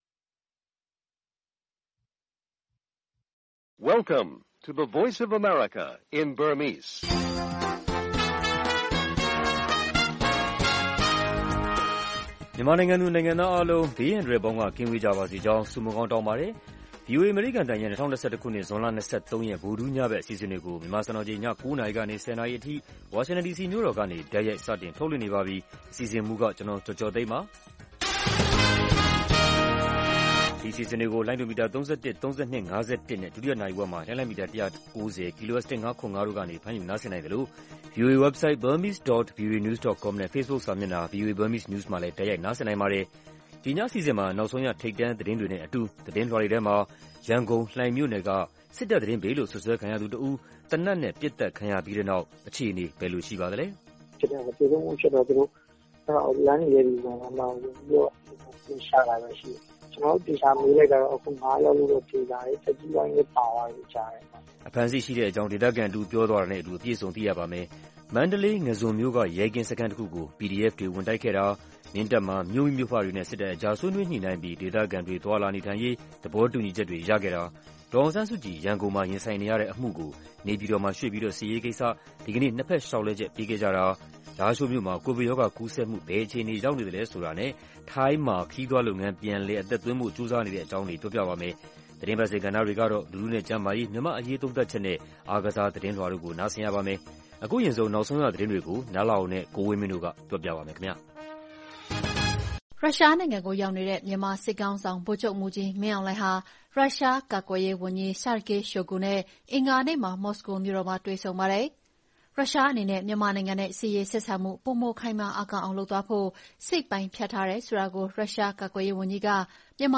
VOA ညပိုင်း ၉း၀၀-၁၀း၀၀ တိုက်ရိုက်ထုတ်လွှင့်မှု